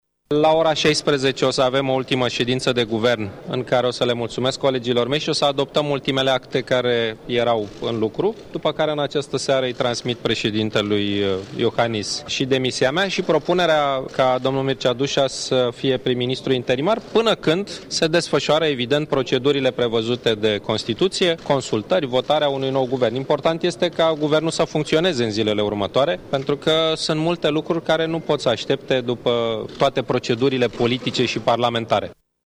Victor Ponta susține că actuala coaliție este solidă și poate asigura guvernarea: